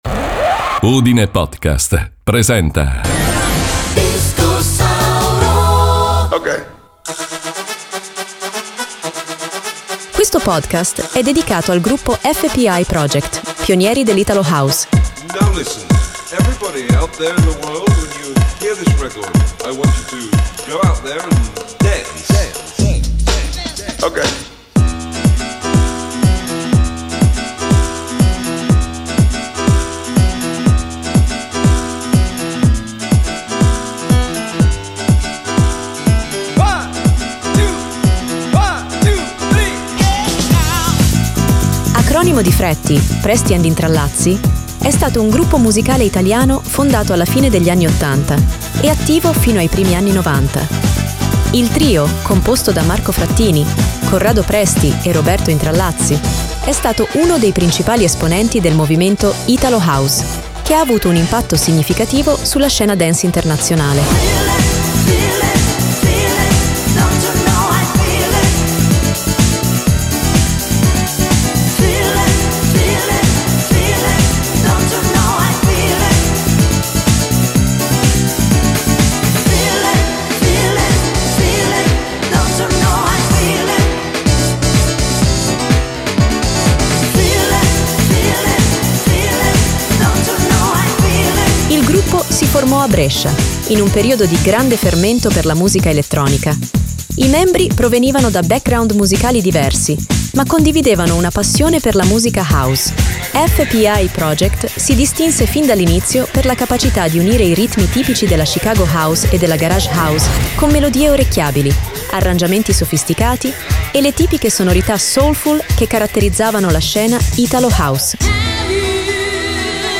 Lo stile del gruppo era caratterizzato da ritmi house energici e trascinanti, melodie soulful e corali, spesso costruite su campionamenti di brani soul e funk, e vocalità potenti.